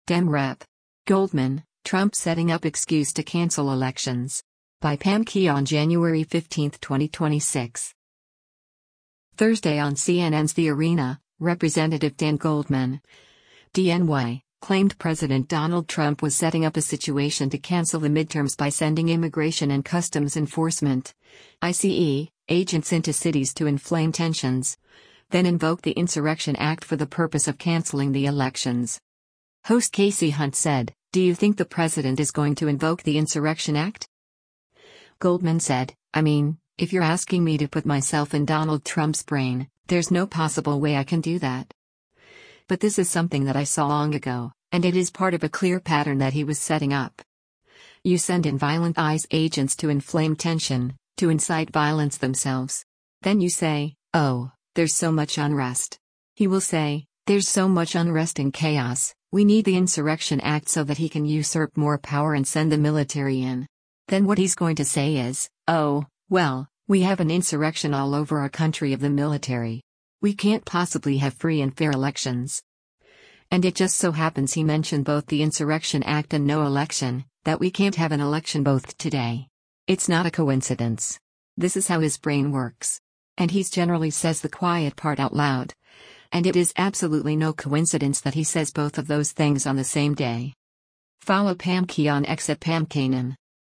Thursday on CNN’s “The Arena,” Rep. Dan Goldman (D-NY) claimed President Donald Trump was “setting up” a situation to cancel the midterms by sending Immigration and Customs Enforcement (ICE) agents into cities to inflame tensions, then invoke the Insurrection Act for the purpose of canceling the elections.
Host Kasie Hunt said, “Do you think the president is going to invoke the Insurrection Act?”